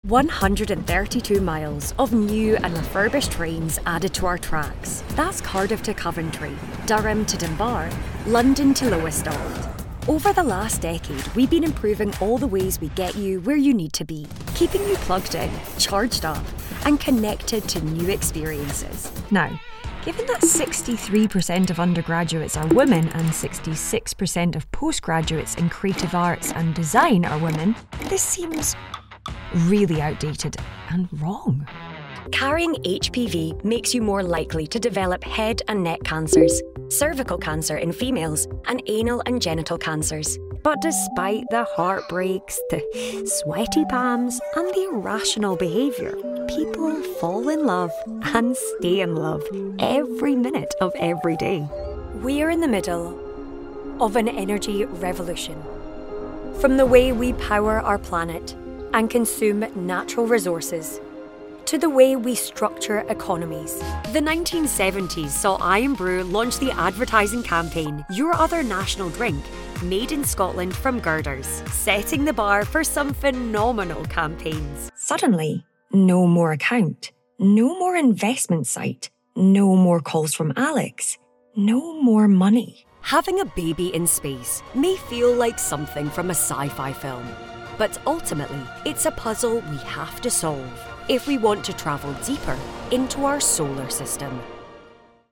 Engels (Schotland)
Veelzijdig, Vriendelijk, Warm, Commercieel, Zakelijk
Corporate